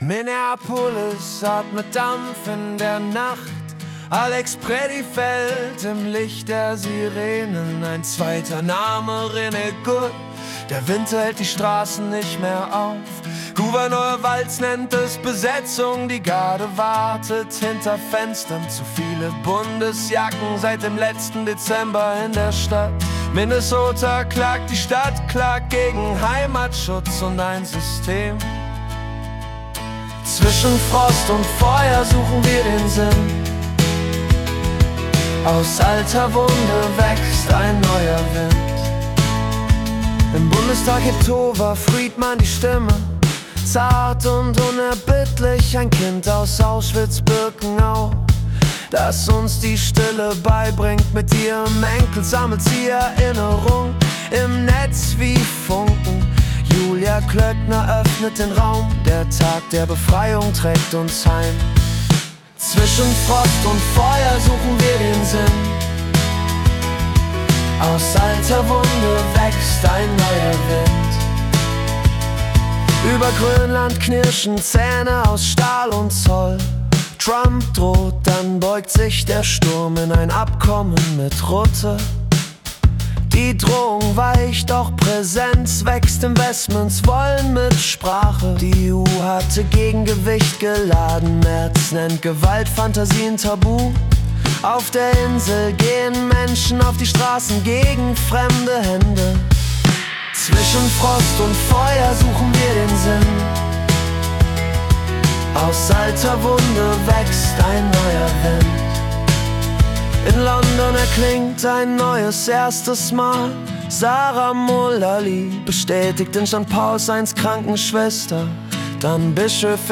Die Nachrichten vom 29. Januar 2026 als Singer-Songwriter-Song interpretiert.